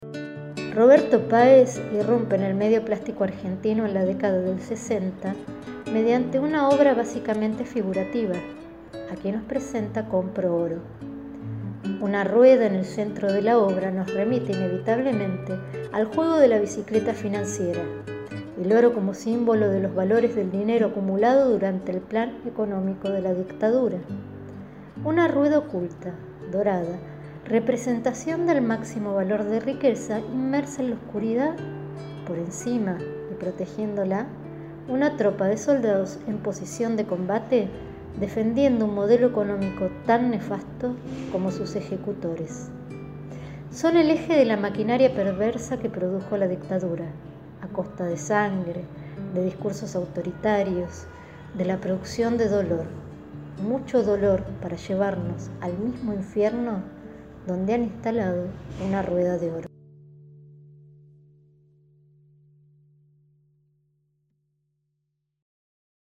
Audioguía adultos